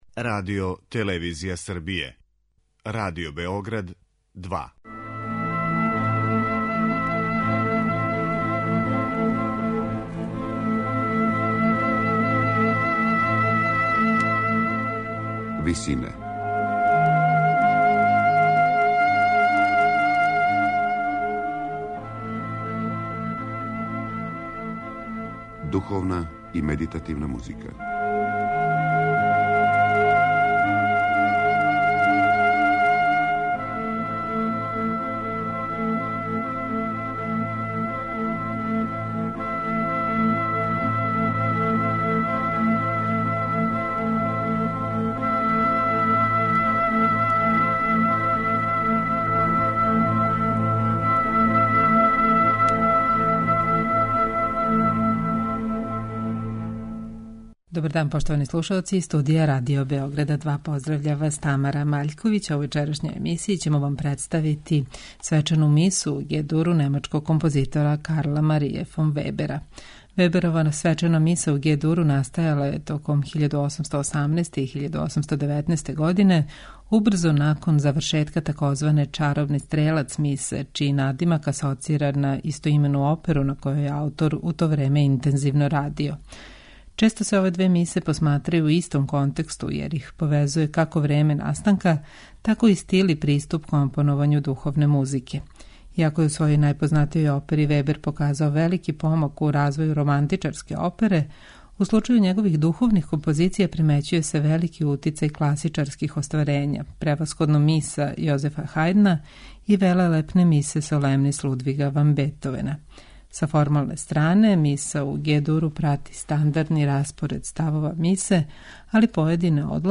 сопран
алт
тенор
бас